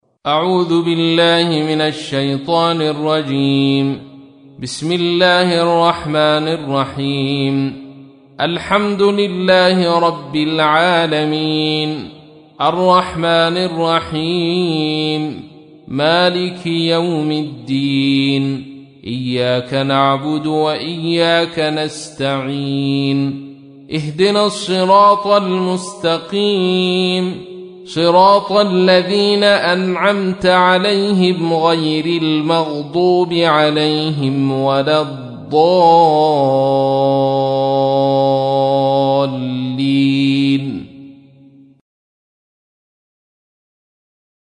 تحميل : 1. سورة الفاتحة / القارئ عبد الرشيد صوفي / القرآن الكريم / موقع يا حسين